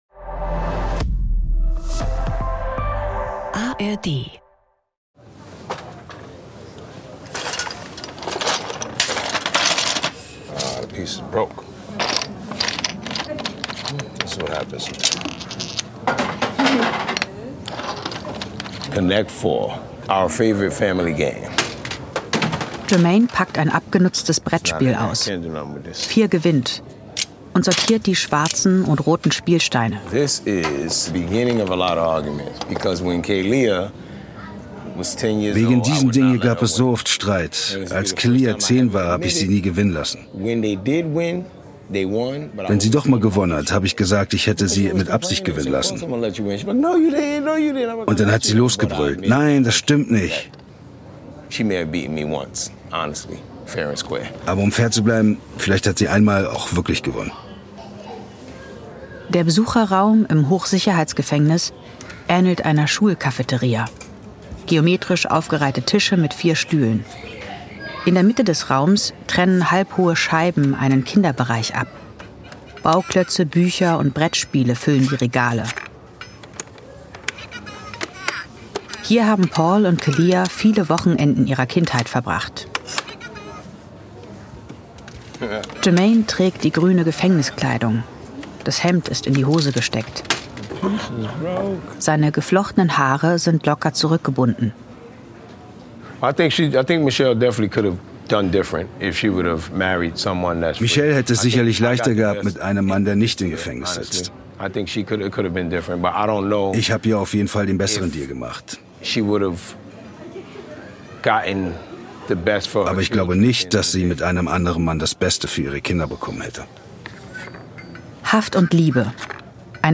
eine True Crime-Story aus konsequent weiblicher Perspektive.
(Stimme der) Erzählerin: